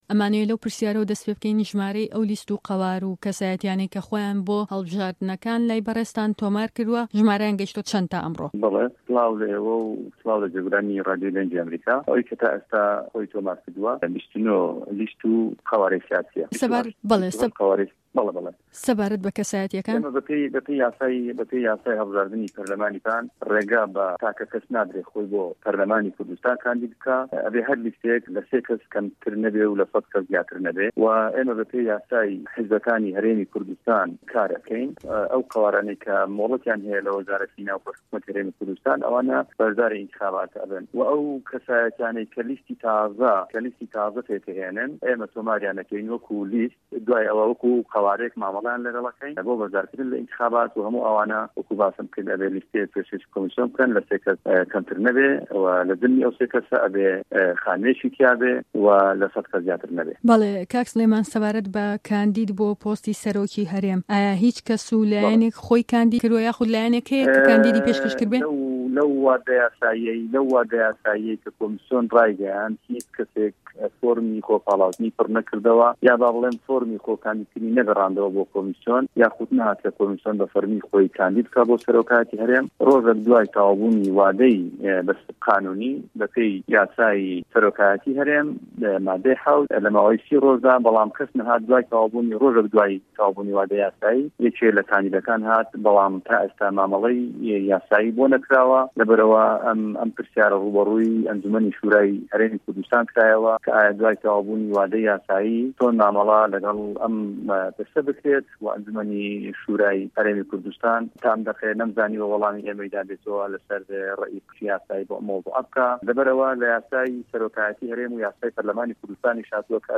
وتووێژ لەگەڵ سلێمان مستەفا